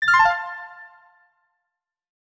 Retro Star Descending 3.wav